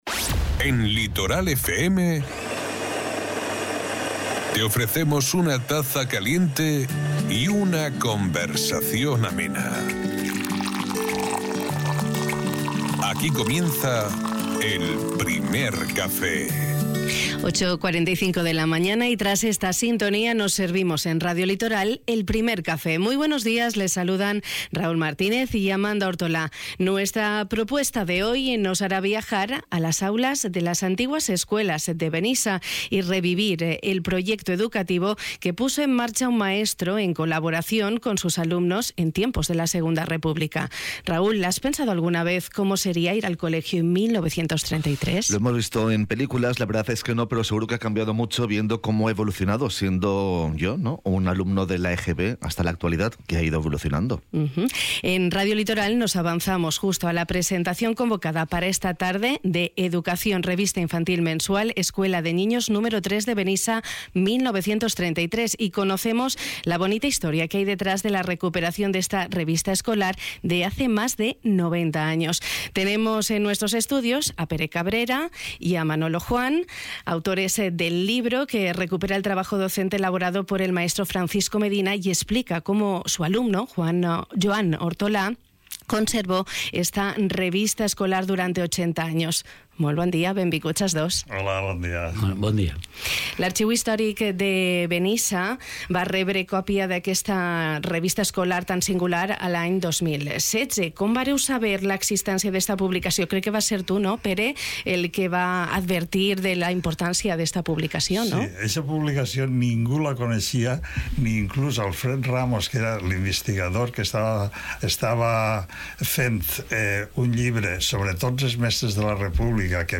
Hem conversat amb dos dels autors